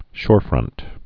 (shôrfrŭnt)